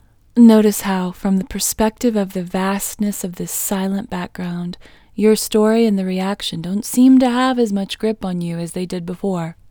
OUT Technique Female English 20